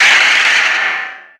Audio / SE / Cries / BANETTE_1.ogg